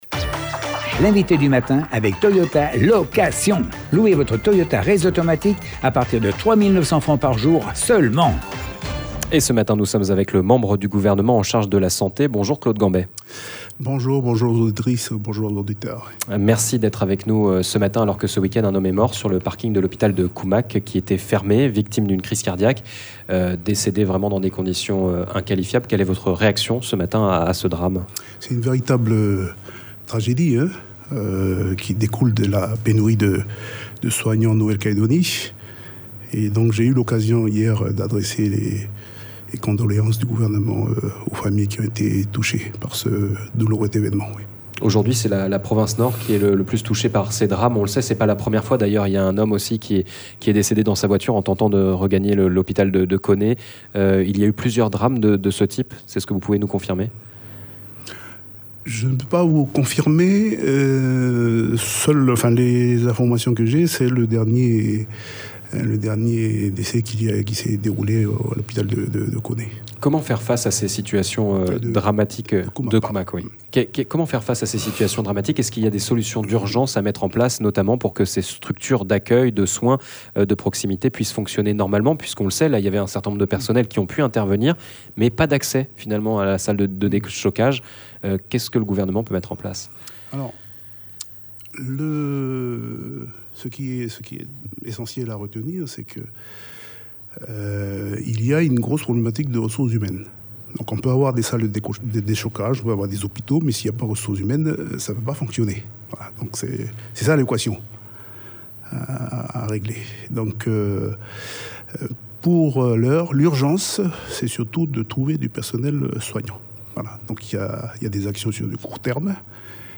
La santé, il en était question avec notre invité : le membre du gouvernement en charge de la santé, Claude Gambey.